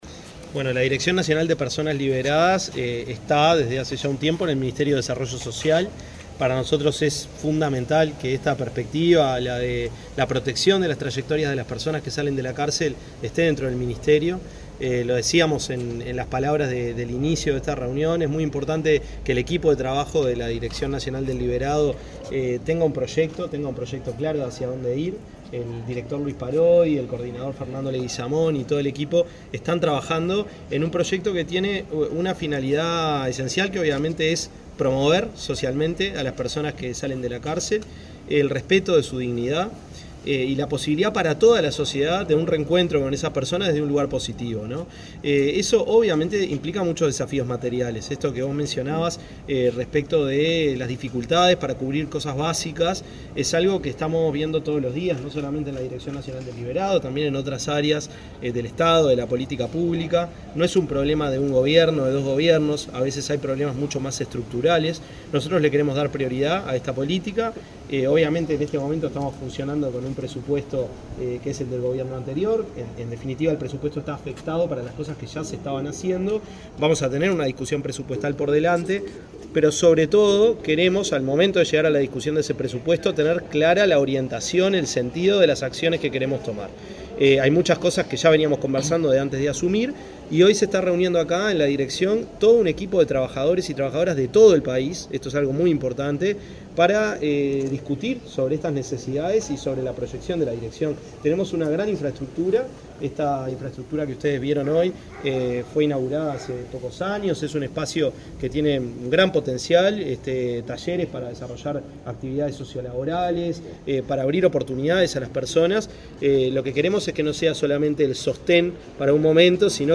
Declaraciones del ministro de Desarrollo Social, Gonzalo Civila
El ministro de Desarrollo Social, Gonzalo Civila, dialogó con la prensa, luego de participar en el primer encuentro nacional de trabajo de este